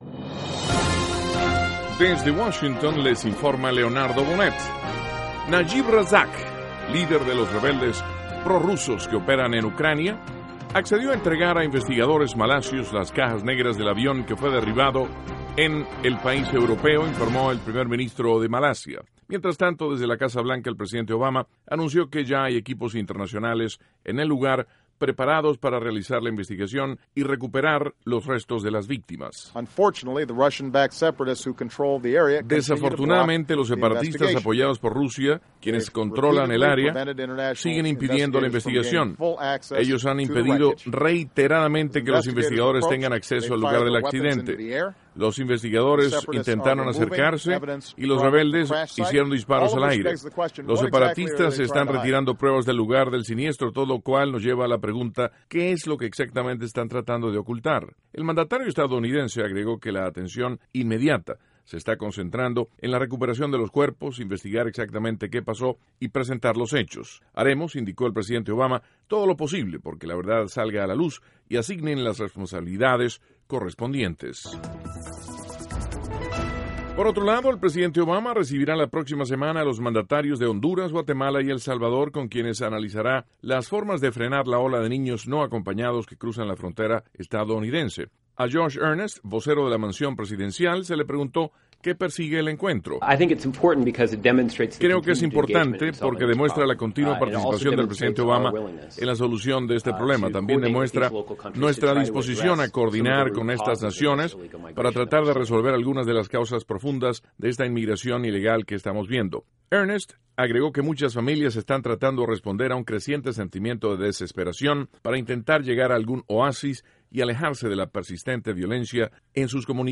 (Sonido Obama) 2.- El presidente Obama recibirá en la Casa Blanca a mandatarios de Guatemala, Honduras y El Salvador. (Sonido Josh Earnest) 3.- Lilian Tintori, esposa del dirigente venezolano, Leopoldo López, conversa con periodistas en el Club Nacional de Prensa, en la capital estadounidense.